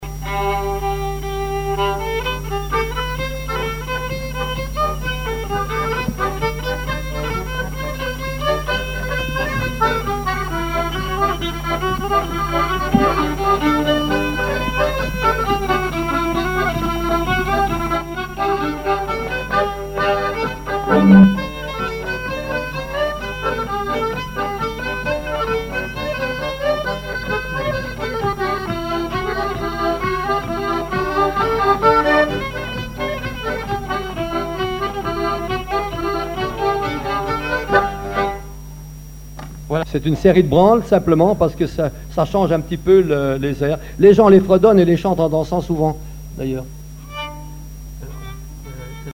danse : polka
collectif de musiciens pour une animation à Sigournais
Pièce musicale inédite